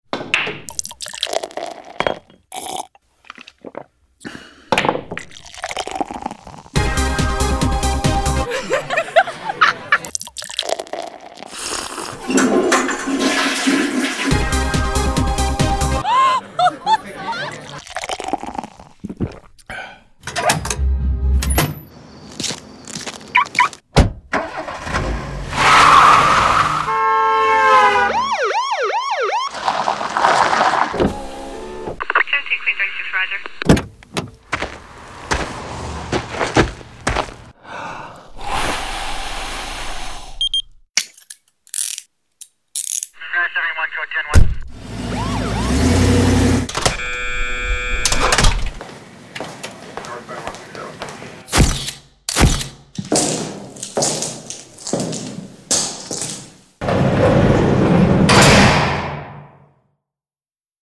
Breaking category conventions is a great way to boost ad recall—in this case, a radio spot with zero words.
uhs_sounds-of-dui_no-tag.mp3